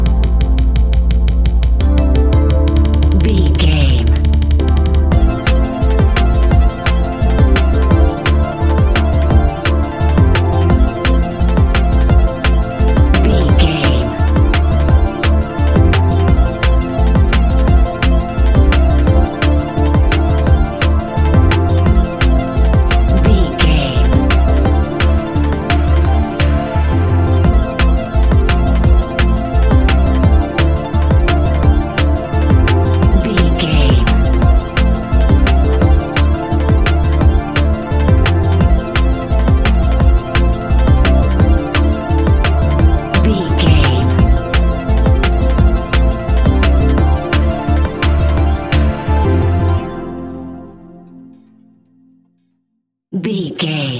Ionian/Major
A♯
electronic
techno
trance
synthesizer
synthwave
instrumentals